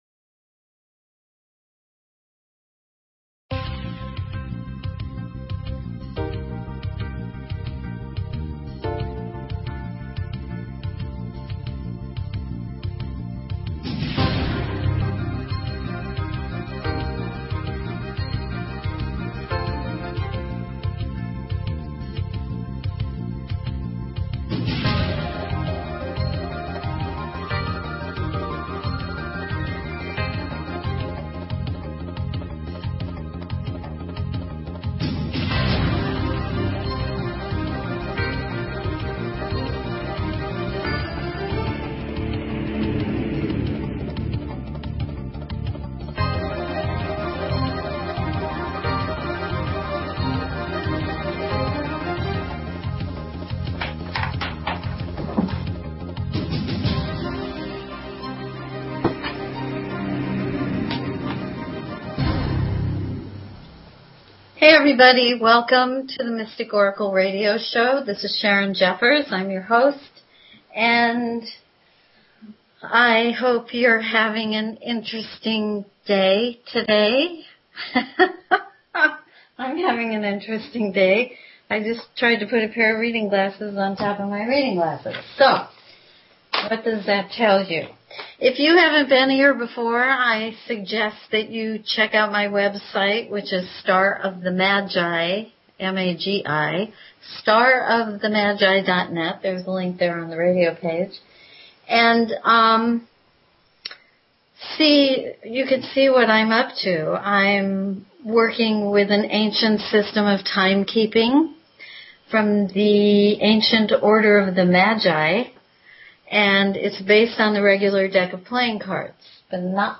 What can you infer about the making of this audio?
Open lines for calls.